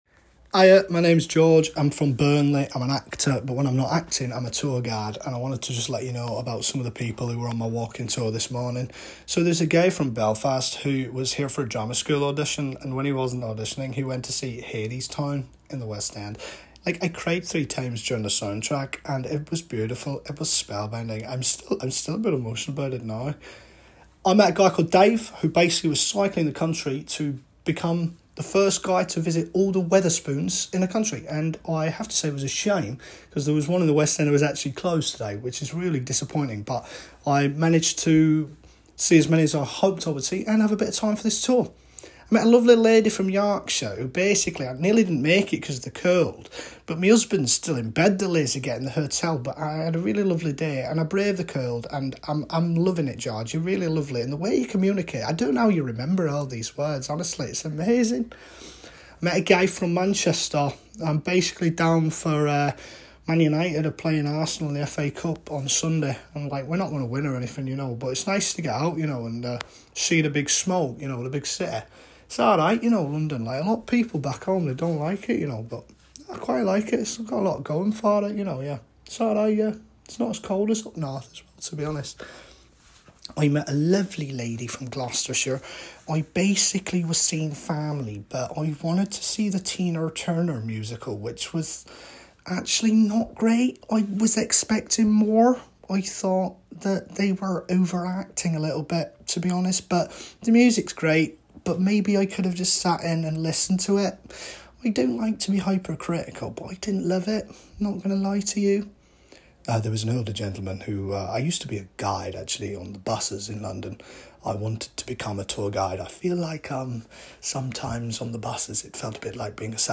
Northern Manchester, RP, Scottish-Standard, Yorkshire